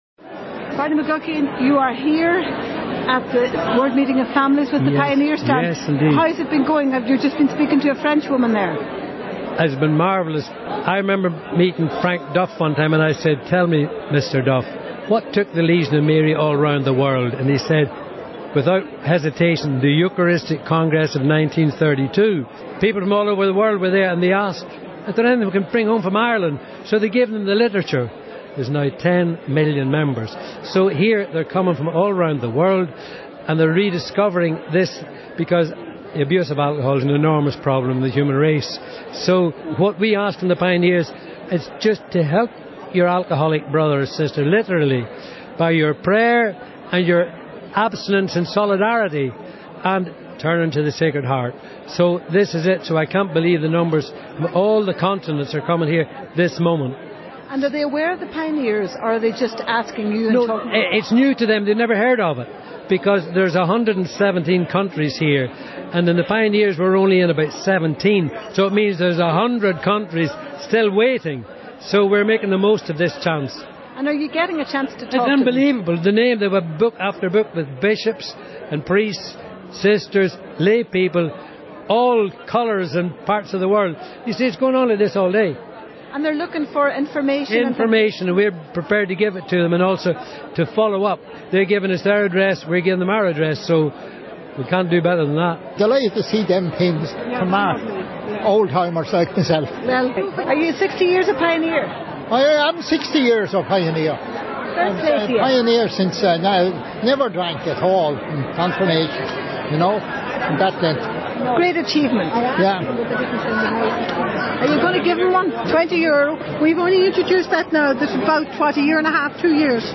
We catch up again with some of the action that took place at the World Meeting of Families Pastoral Congress in the RDS at the end of August.